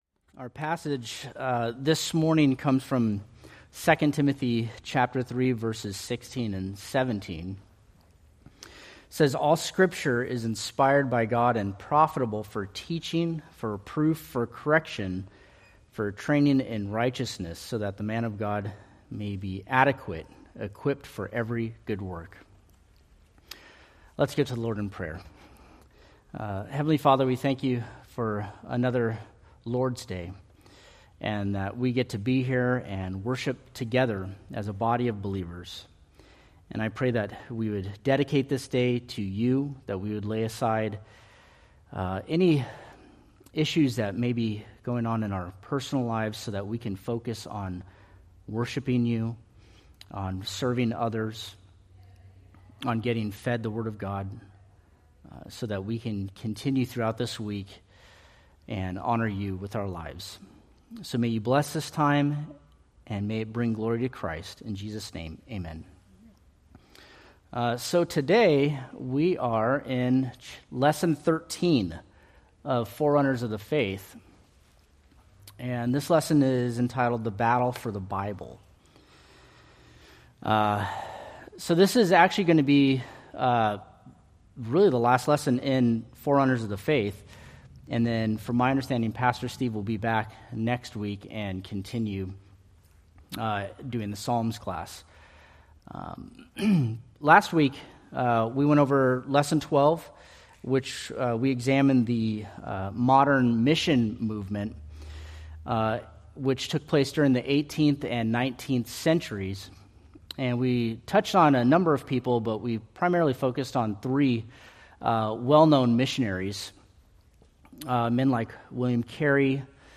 Date: Jun 1, 2025 Series: Forerunners of the Faith Grouping: Sunday School (Adult) More: Download MP3